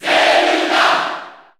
Category: Crowd cheers (SSBU) You cannot overwrite this file.
Zelda_Cheer_Japanese_SSB4_SSBU.ogg